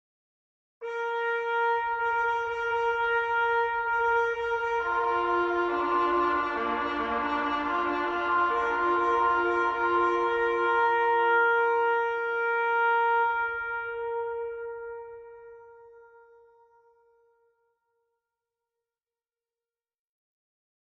Trumpet Call from the Overture Leonore No.3 by Ludwig van Beethoven
Leonore No.3 is one of these overtures, and in the middle of the piece there is the following trumpet call, which is played by a trumpet situated off-stage so that it sounds distant. It was surely Beethoven's intention to suggest a bugle call at this point, though in this particular case the notes are not identical to those of a bugle.
trumpet-call-from-beethoven-overture-leonore-no3.mp3